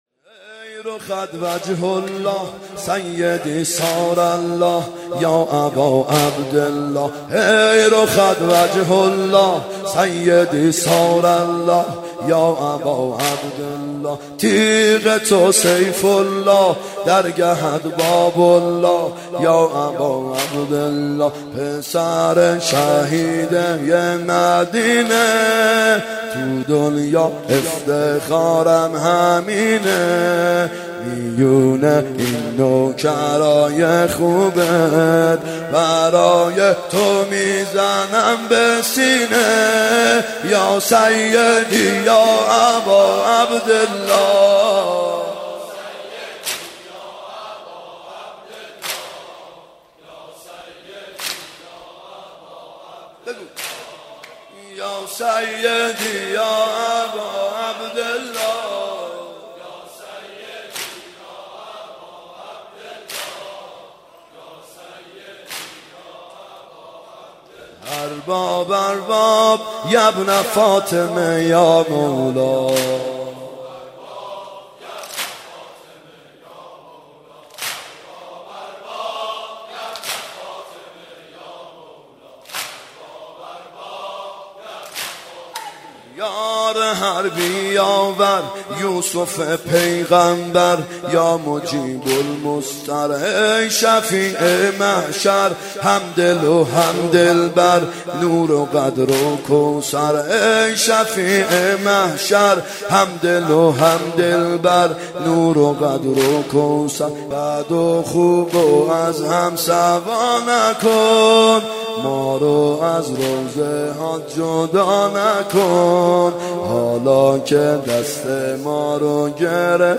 محرم 92 ( هیأت یامهدی عج)